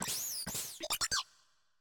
Cri de Gribouraigne dans Pokémon Écarlate et Violet.